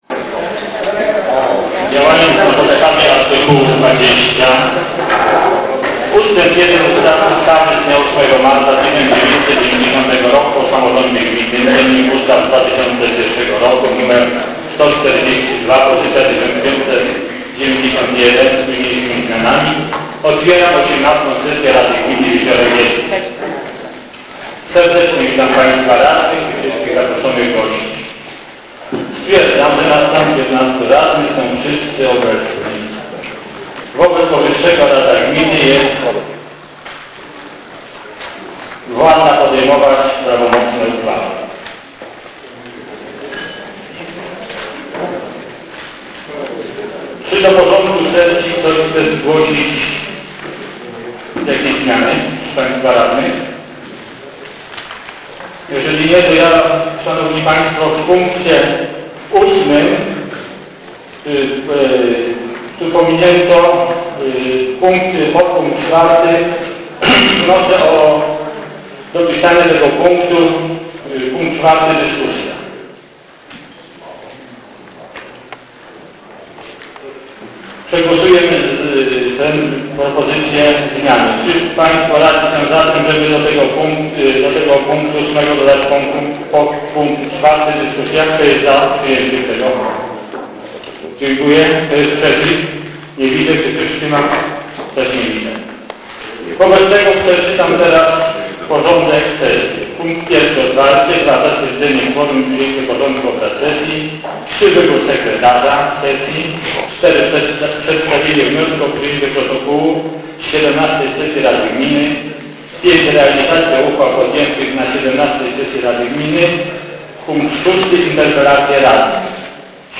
Nagranie 17 sesji Radny Gminy